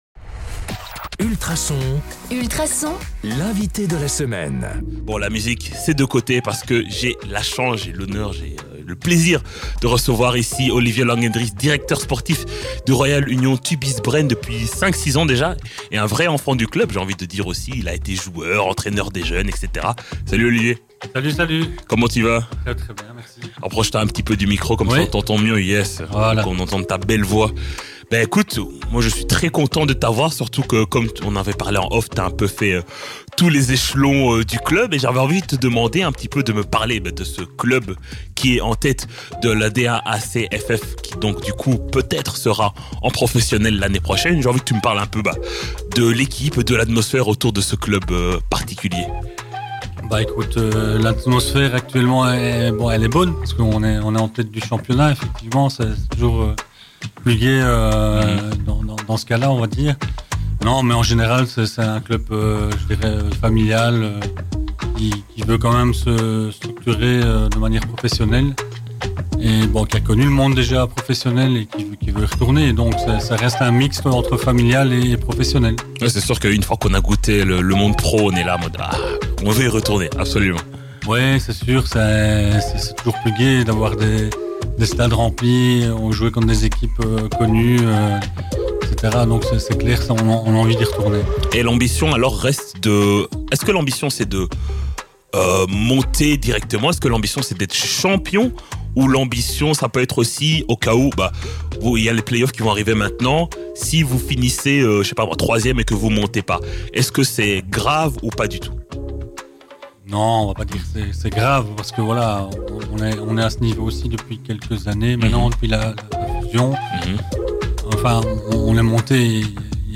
Dans cet échange passionnant, il nous dévoile :